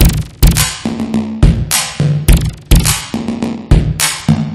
Light That Ass On Fire Drum Loop.wav